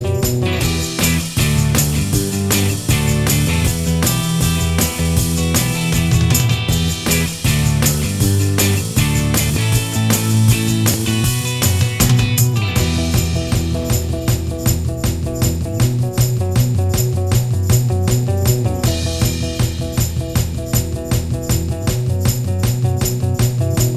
Minus Sitar Rock 3:57 Buy £1.50